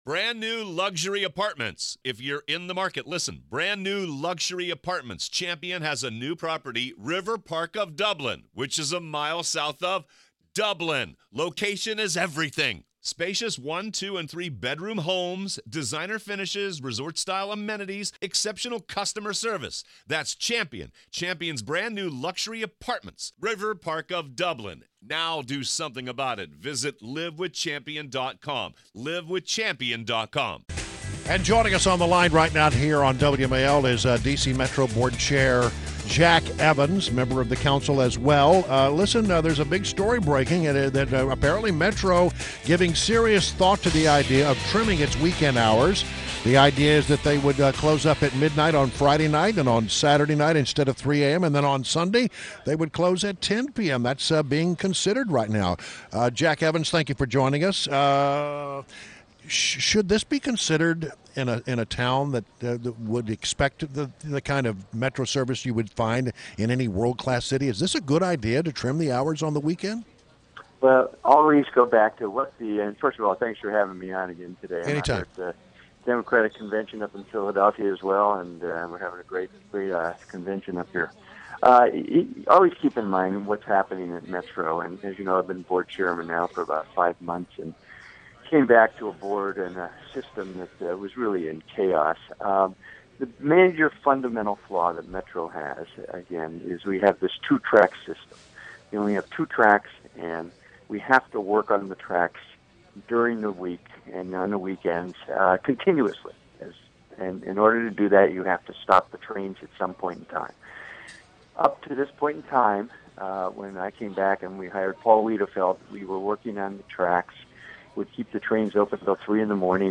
WMAL Interview - Jack Evans - 07.27.16